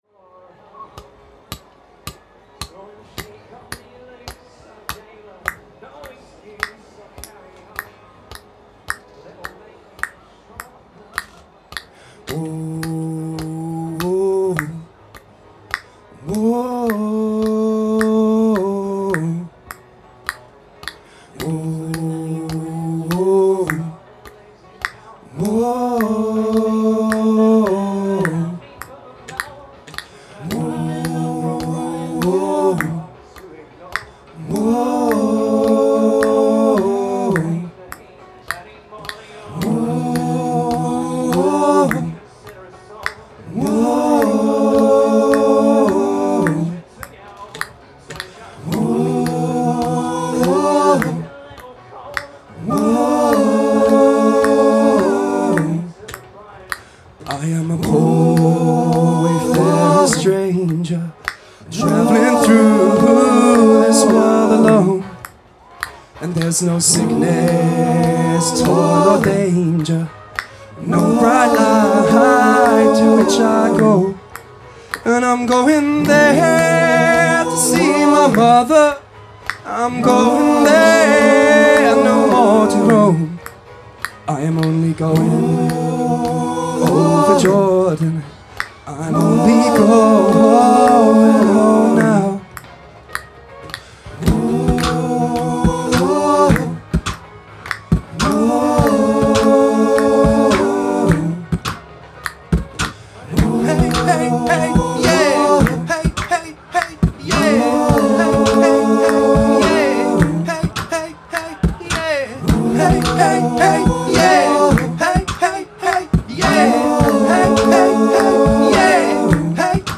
Recorded Live at Tentertainment 2012